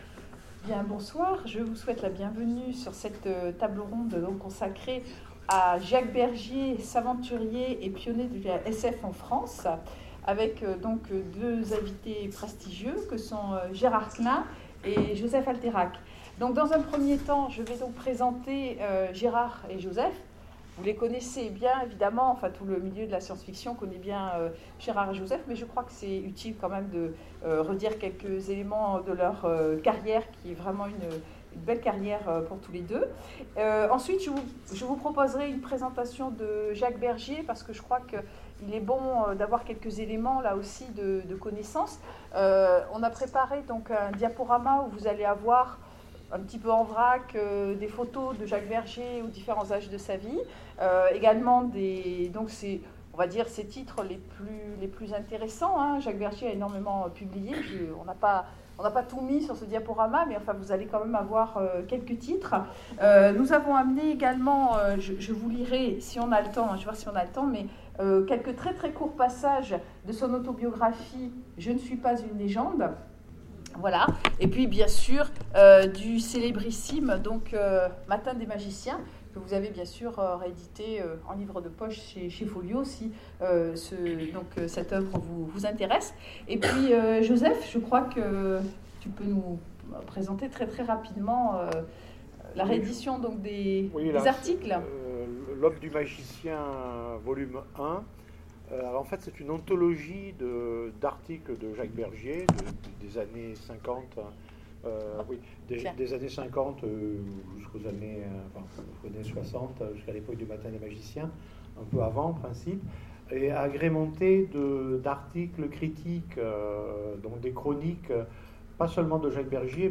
Sèvres 2015 : Conférence Jacques Bergier savanturier et pionnier de la SF en France
rencontres_sevres_2015_conference_jacques_bergier_SF_france_ok.mp3